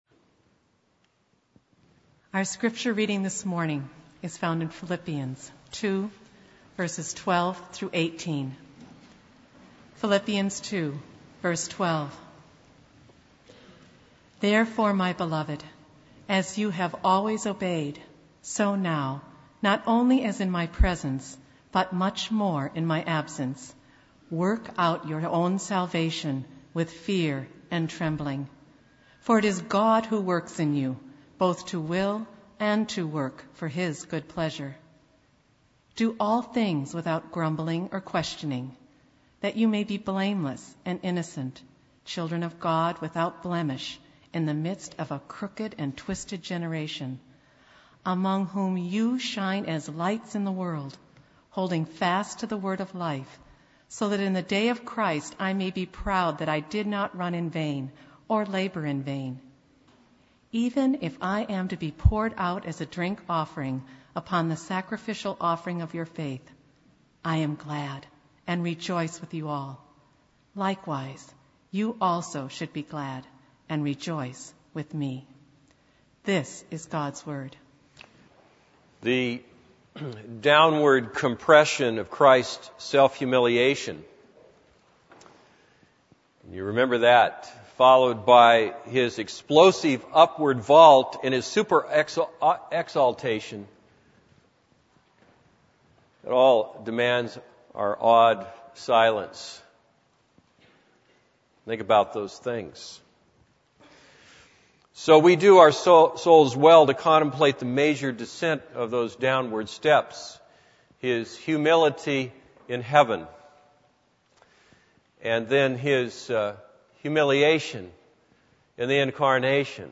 This is a sermon on Philippians 2:12-18.